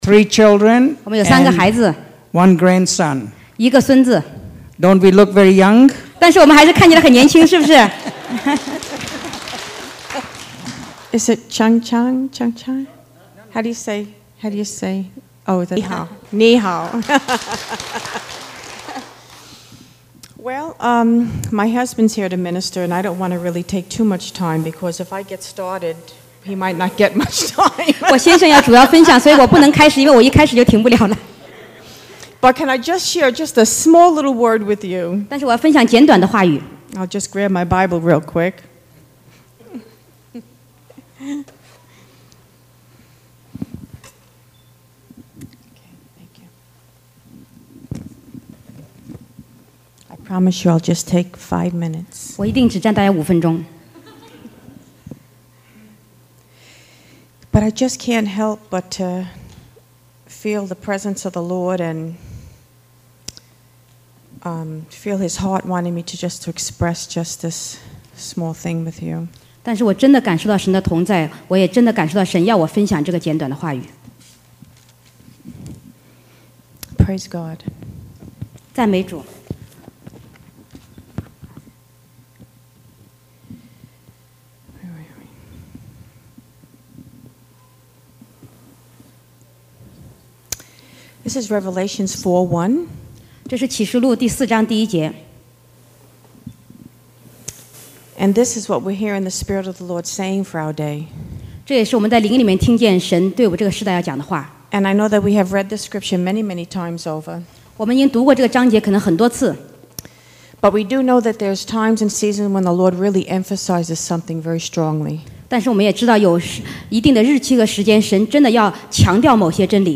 牧师夫妇